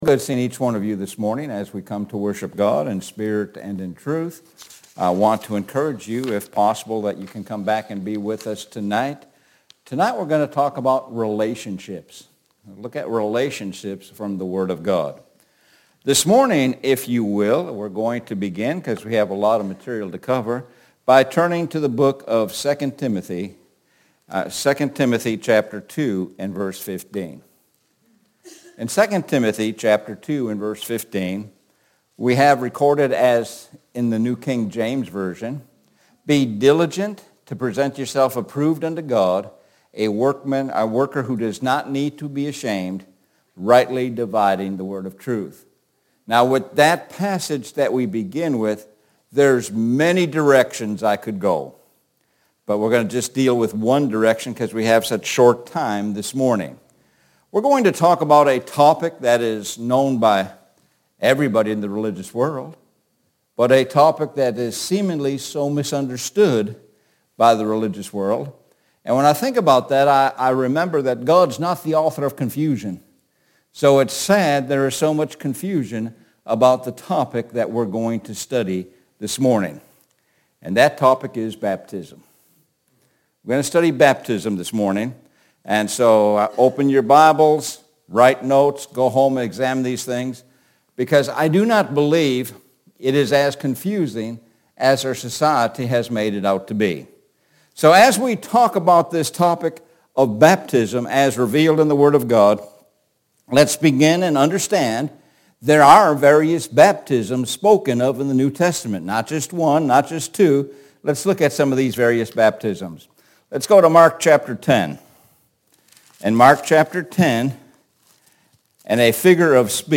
Sun AM Sermon